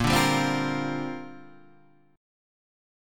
A#6add9 Chord